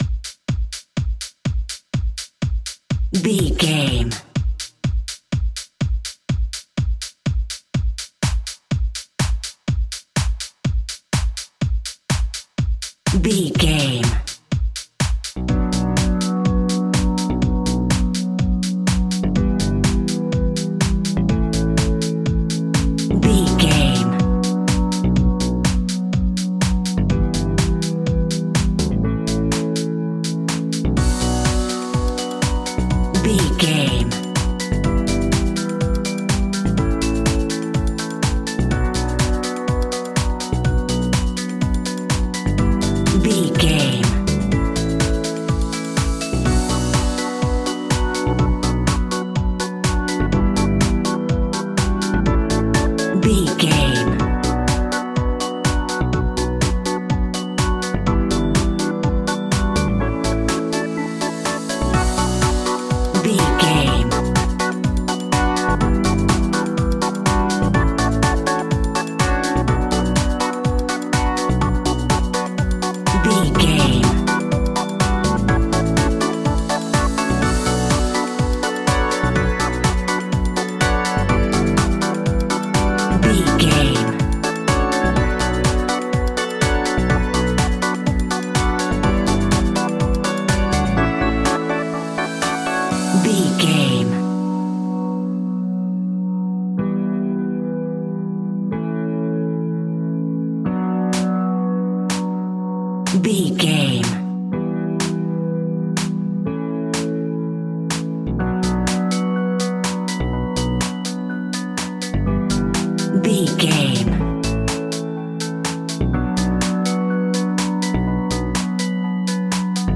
Aeolian/Minor
groovy
hypnotic
uplifting
synthesiser
drum machine
electric guitar
funky house
nu disco
upbeat
funky guitar
wah clavinet
synth bass
horns